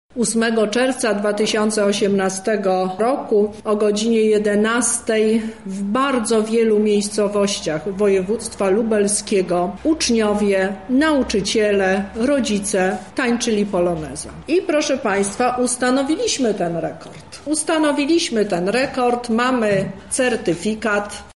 O szczegółach mówi Teresa Misiuk, kurator oświaty w Lublinie: